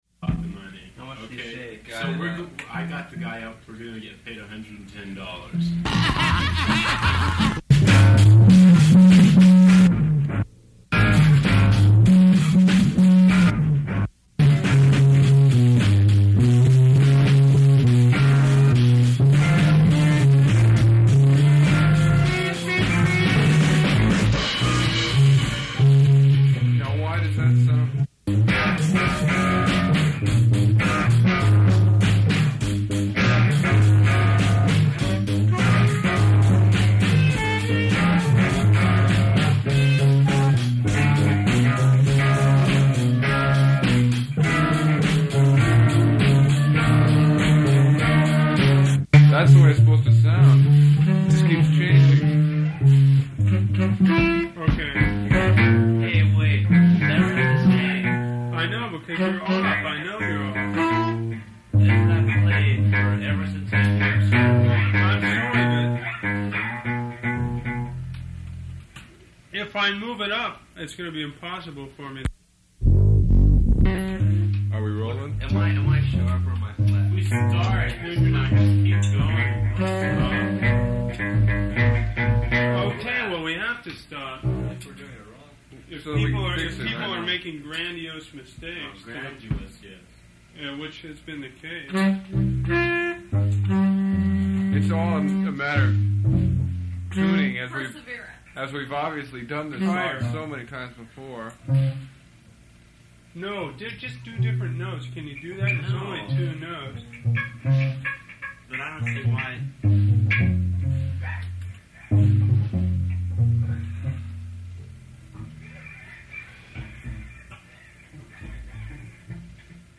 Buchlaシンセサイザーもガラクタも面白い音を出すための道具として彼らにとって同等だったようだ。
CalArts電子音楽スタジオでの録音だけではなく、日常的に作っていた奇妙な音群から厳選した内容のレコードだ。